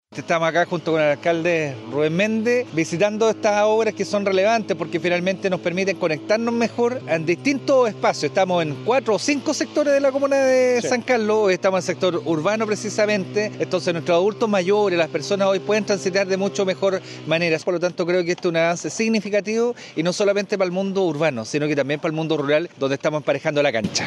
El gobernador Óscar Crisóstomo recalcó la relevancia del proyecto para la equidad urbana y rural: “Estas obras mejoran la conectividad y entregan mayor seguridad, sobre todo para nuestros adultos mayores. Es un avance significativo, no solo para el mundo urbano, sino también para el mundo rural, donde estamos emparejando la cancha”.
Gobernador-Oscar-Crisostomo.mp3